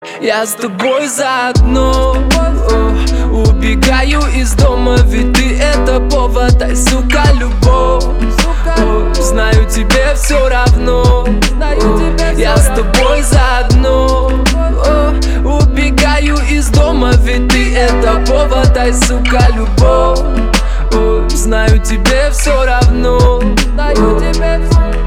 • Качество: 320, Stereo
мужской вокал
лирика
грустные
русский рэп